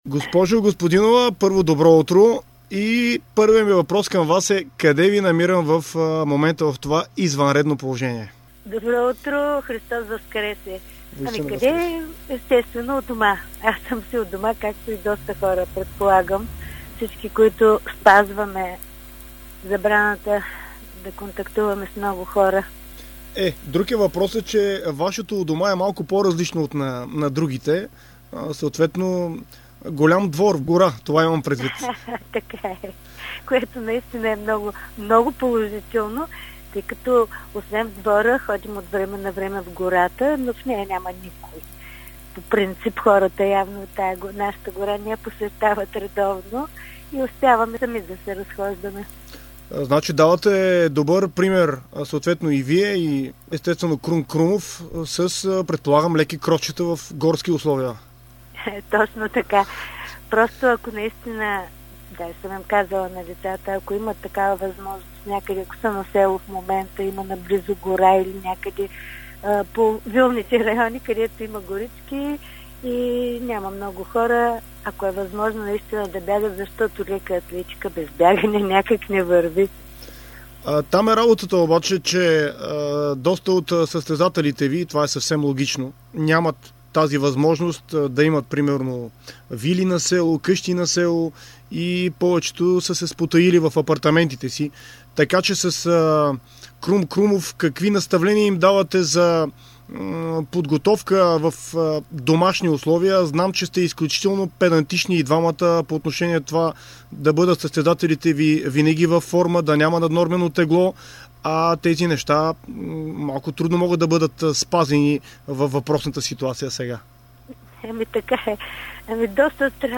За това, как се готвят те в извънредното положение у нас, разказва самата треньорка в интервю за Дарик радио и dsport.